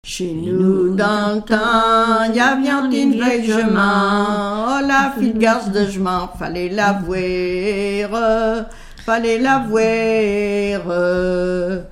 Lettrées patoisantes
Pièce musicale inédite